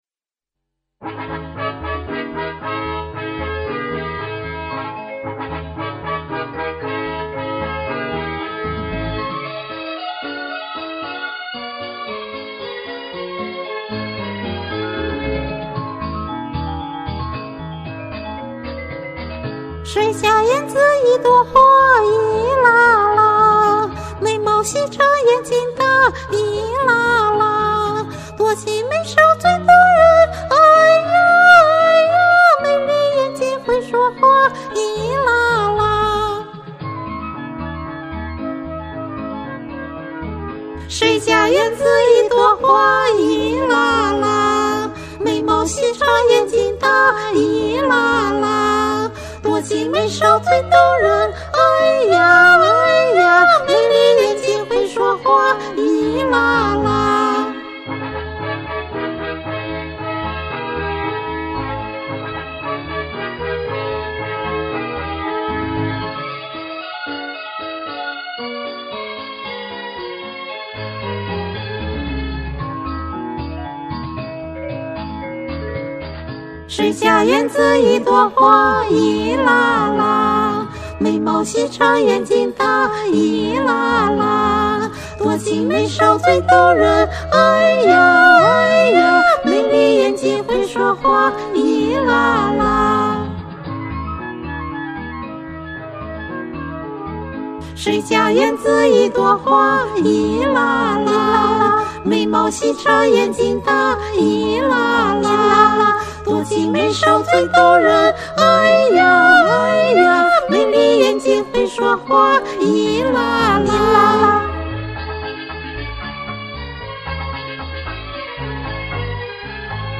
小合唱
《依拉拉》      新疆哈薩克族民歌
我在網上找到這首歌的兩個版本歌譜，個別音有出入，我挑了一版和我手頭的伴奏比較符合的來唱。
《依拉拉》是一首4/4節拍的歌曲，歌中，把姑娘比作園子裡一朵花，進行了熱烈地讚美，歌詞是：